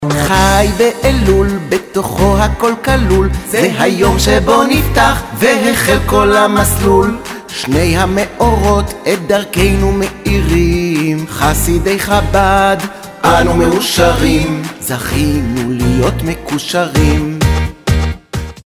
צלצול לח"י אלול
צלצול-חי-אלול.mp3